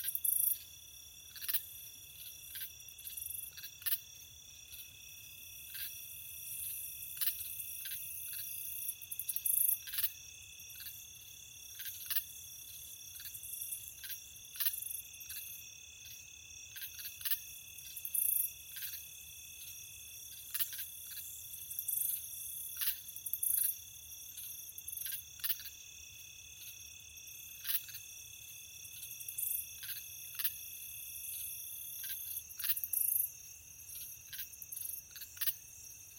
insectday_17.ogg